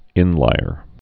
(ĭnlīər)